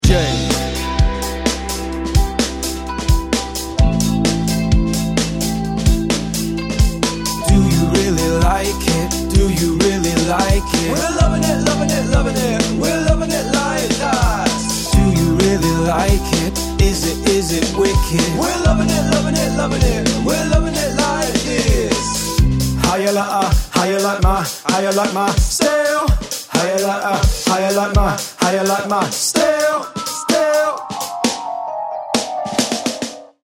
3-piece and 4-piece London Function Band